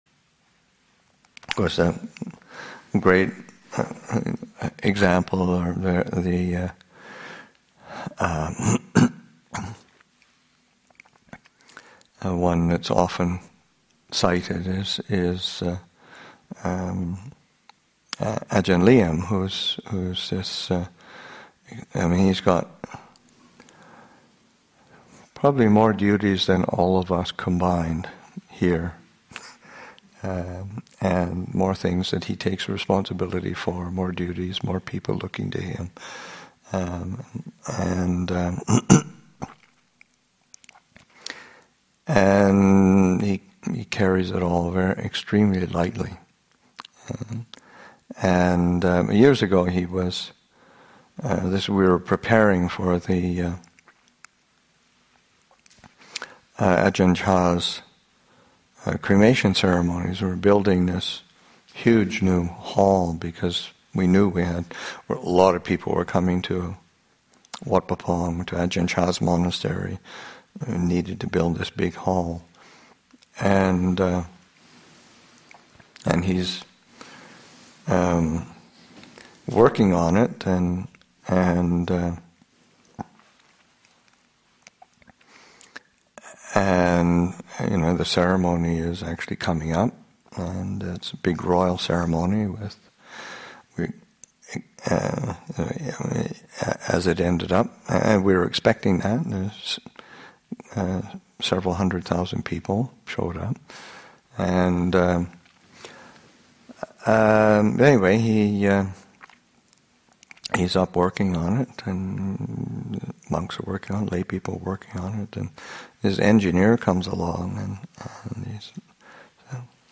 Abhayagiri Buddhist Monastery in Redwood Valley, California and online